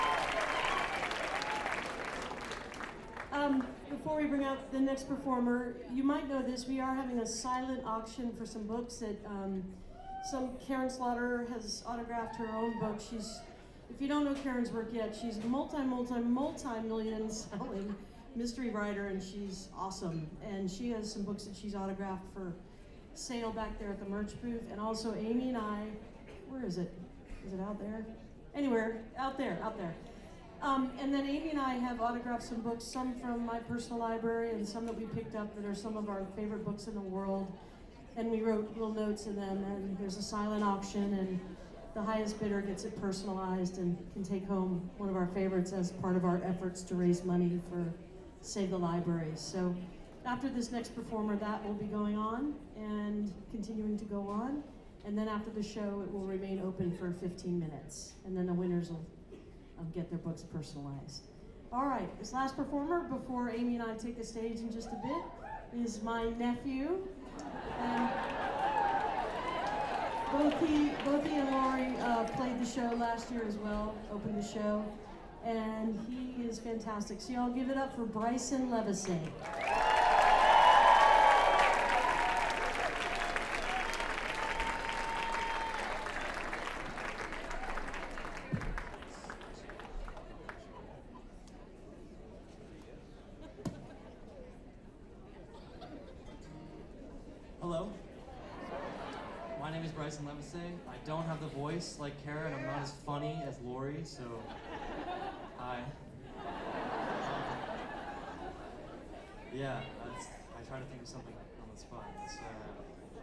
lifeblood: bootlegs: 2016-01-03: terminal west - atlanta, georgia (benefit for save the libraries)